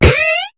Cri de Moufouette dans Pokémon HOME.